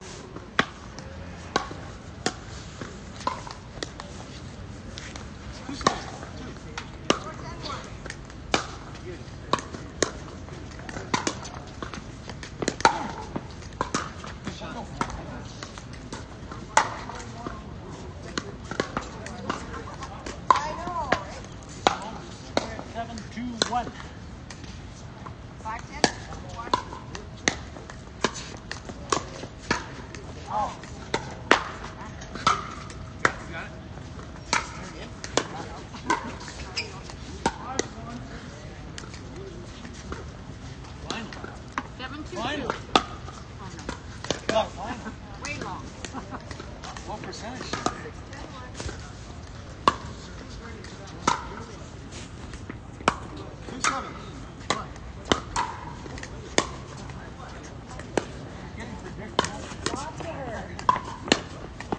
Listen to the sound of 4 pickleball courts
Sound from 4 pickleball courts.mp3